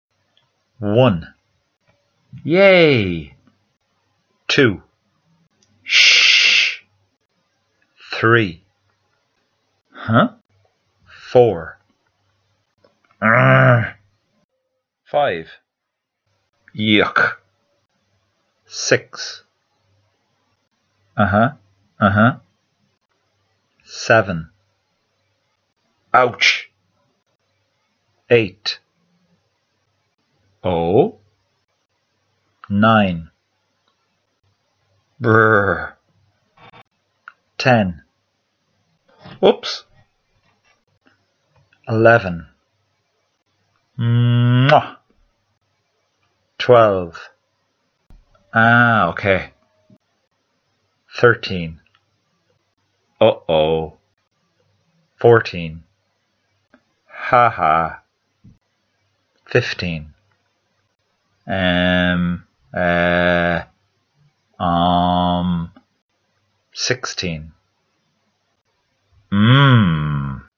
Interjection Sounds in English
Listen to the audio of sixteen different interjection sounds.
interjections.mp3